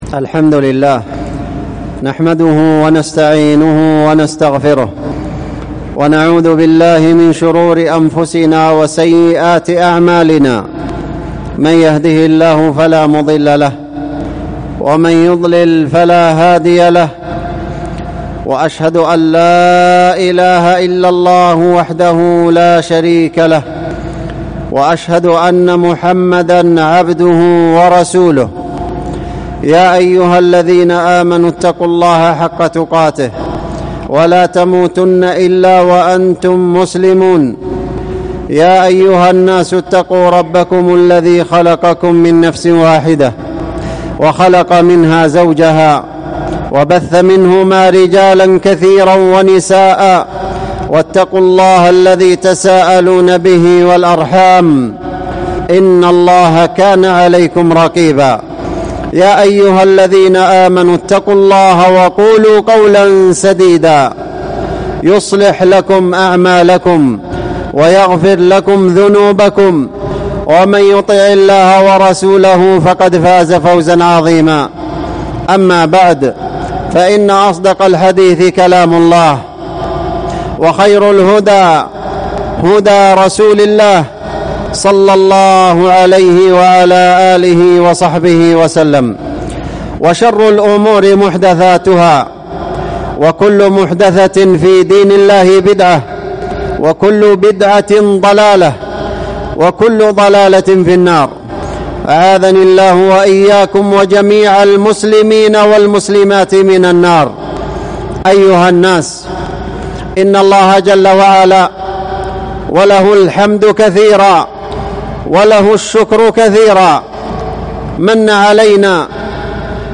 تذكير الأنام بفضائل امة الإسلام | الخطب والمحاضرات